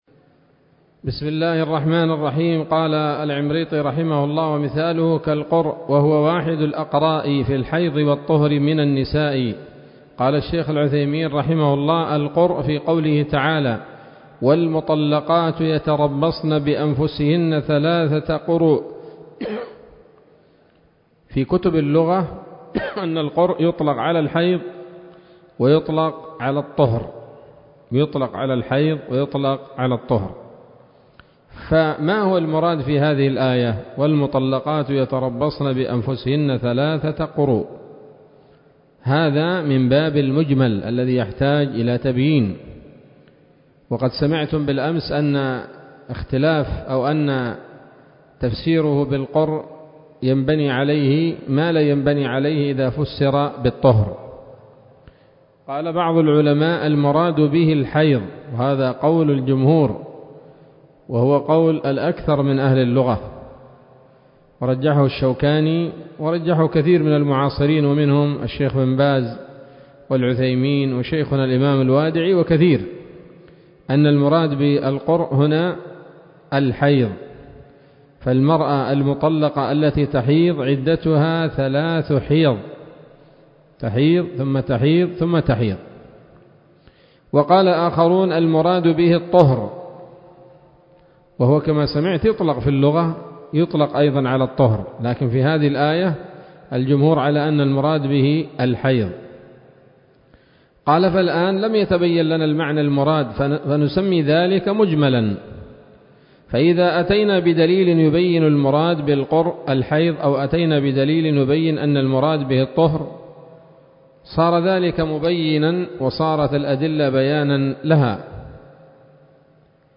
الدرس الخامس والأربعون من شرح نظم الورقات للعلامة العثيمين رحمه الله تعالى